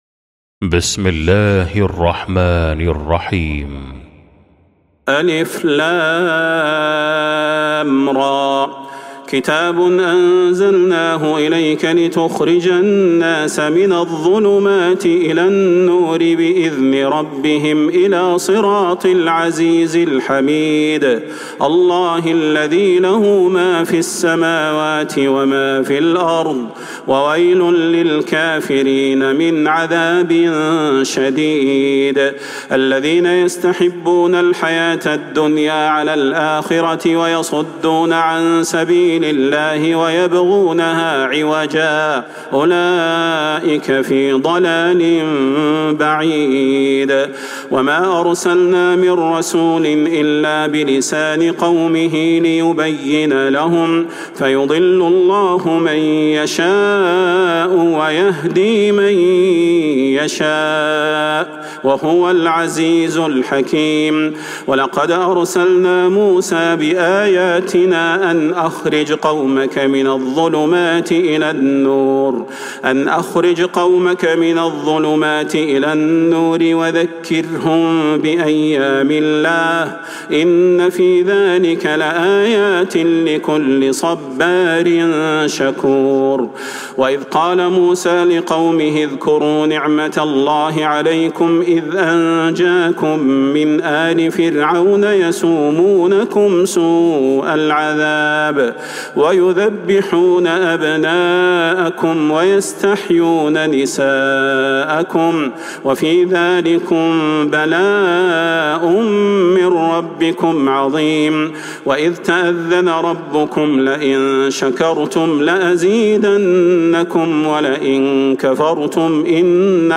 سورة إبراهيم | Surah Ibrahim > مصحف تراويح الحرم النبوي عام 1446هـ > المصحف - تلاوات الحرمين